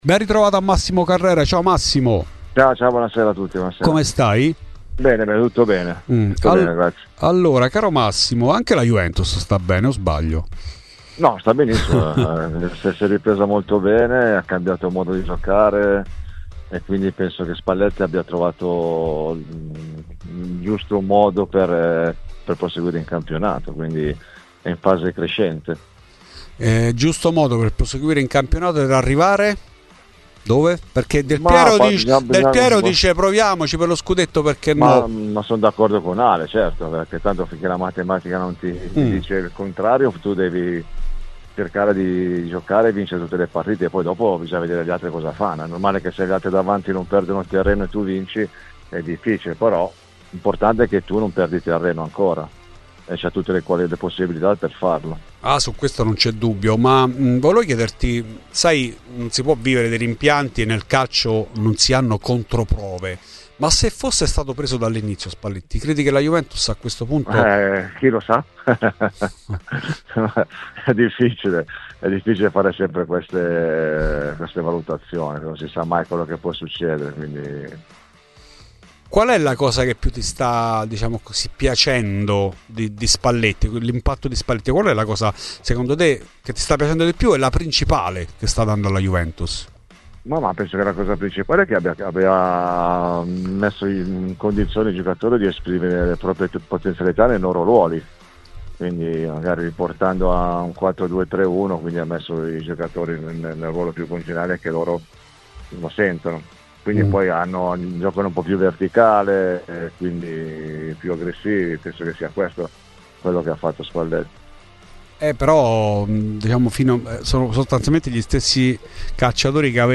L'ex Juve Massimo Carrera è intervenuto a Radio Bianconera, durante Fuori di Juve, per parlare del momento della squadra di Spalletti: "Sta benissimo, si è ripresa molto bene, Spalletti ha trovato il giusto modo per proseguire in campionato.